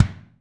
BD 1H.wav